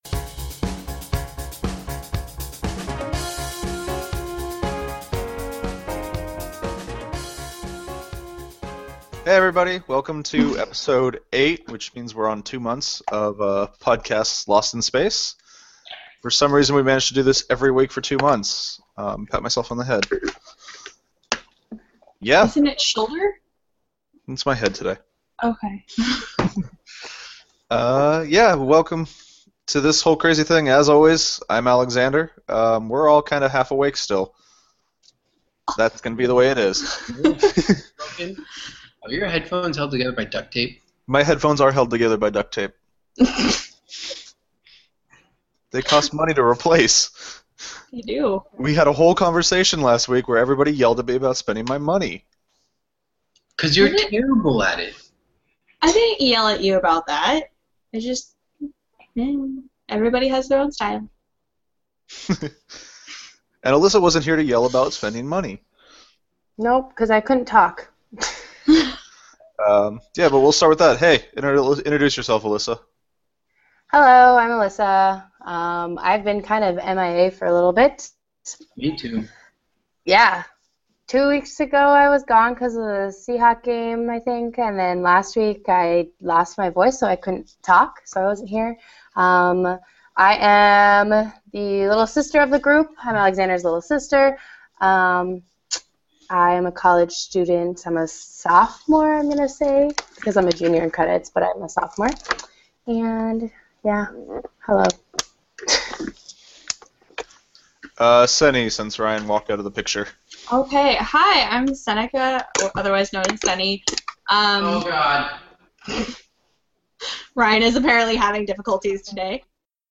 Join the Podcast Lost In Space for a mildly technical issue ridden episode of Podcast Lost in Space! We talk a bit about crisis’s a bit about people’s lives and Relationships.